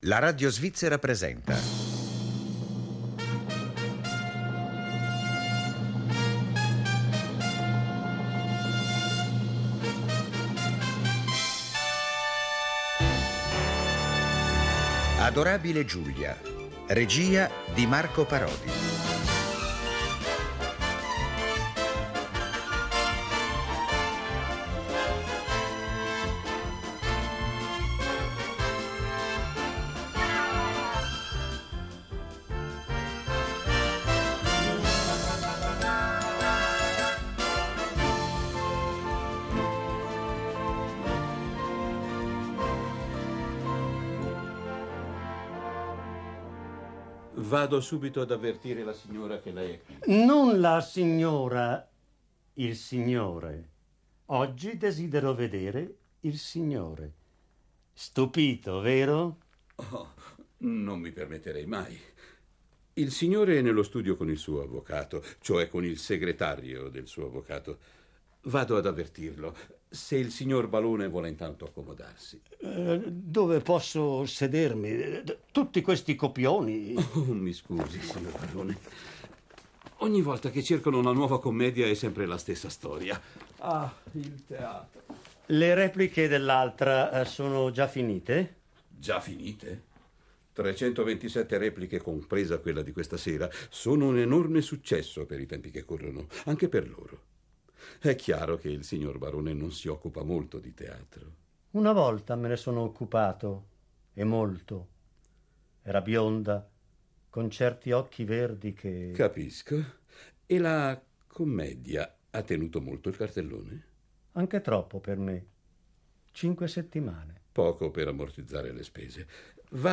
"Adorabile Giulia" è una commedia brillante ed ironica, che a suon di colpi di scena gioca sul livello meta teatrale tra realtà e finzione.